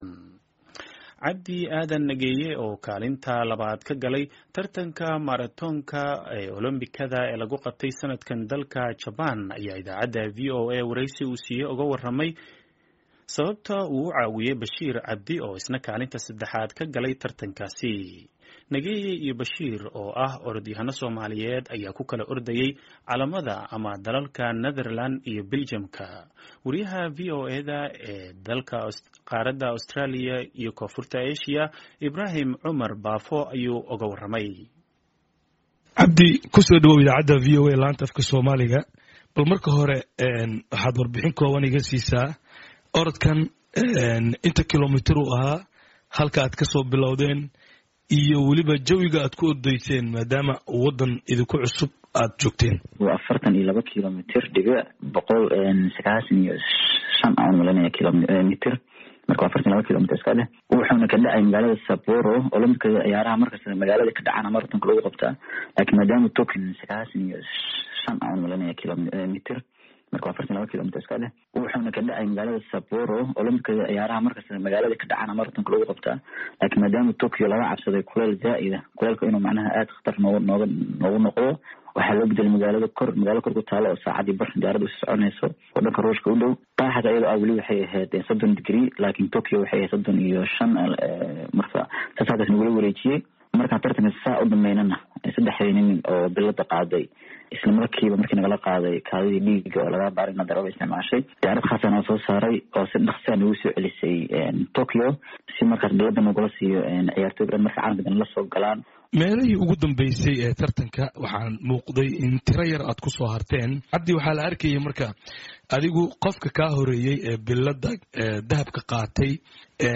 Wareysi: Orodyahan Nageeye oo sharraxaya qaabkii uu u caawiyay Bashiir Cabdi